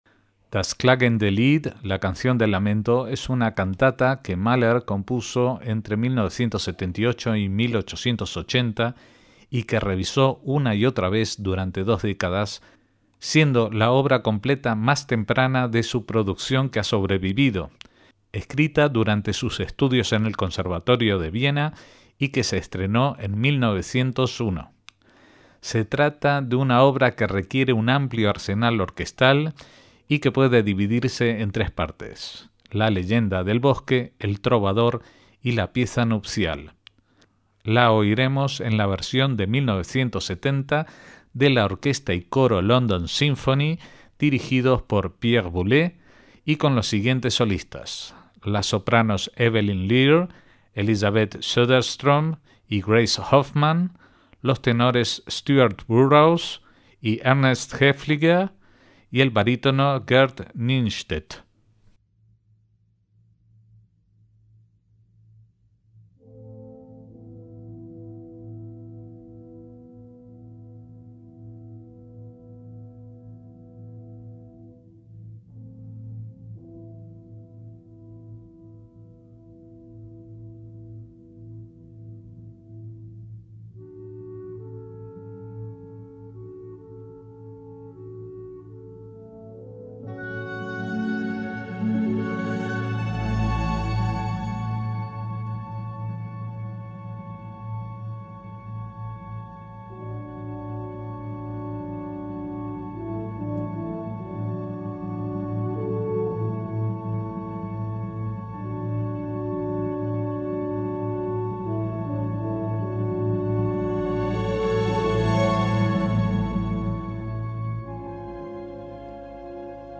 Das Klagende Lied, por Pierre Boulez al frente de la London Synphony, coro y solistas
MAHLER: EL COMPOSITOR NACIONALISTA DE LA ASIMILACIÓN – Das klagende Lied (literalmente, «La canción del lamento») es una cantata que Mahler compuso entre 1878 y 1880, aunque la corrigió en varias ocasiones.
A pesar de ello es una obra muy grande y compleja, larga y que requiere una gran orquesta.